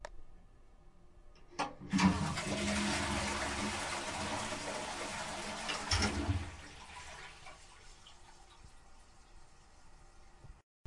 厕所漏水
描述：破碎的厕所似乎漏了太多:)
标签： 管道 冲洗 冲洗 厕所 浴室 厕所
声道立体声